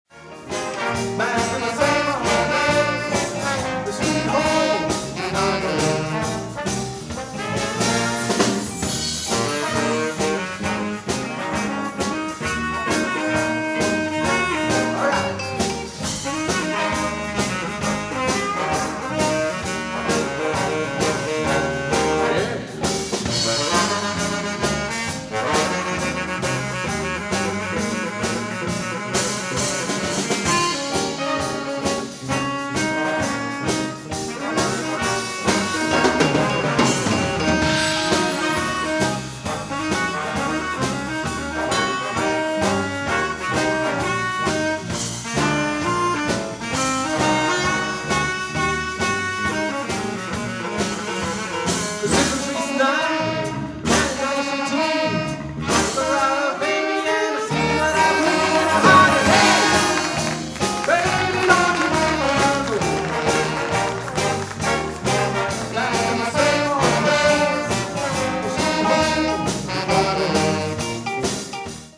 Konzert 2004 -Download-Bereich